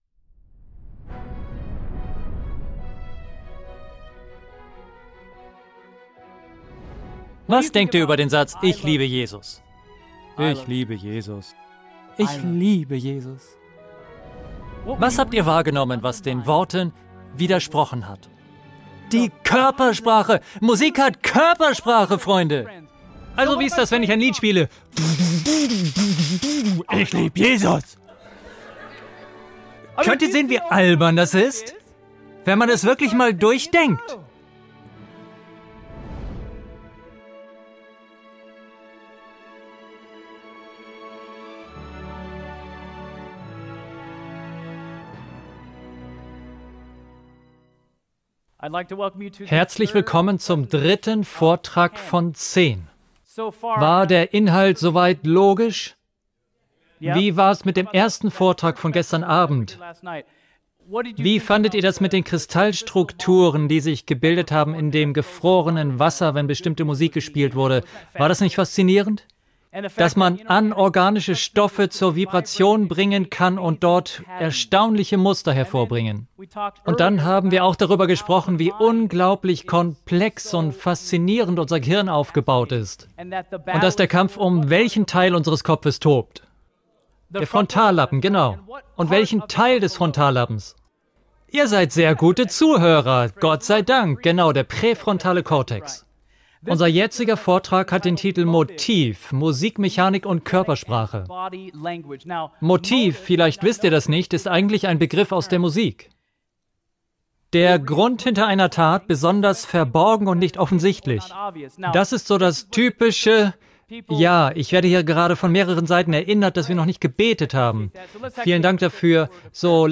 Wusstet ihr, dass jedes je komponierte Musikstück ein bestimmtes Motiv und Körpersprache hat? In diesem Vortrag kann man mehr darüber erfahren.